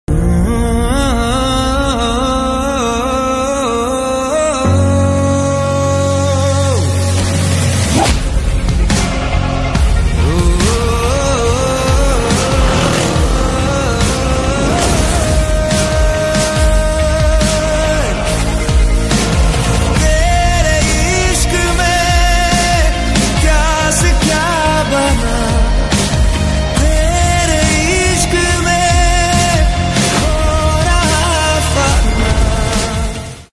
romantic tune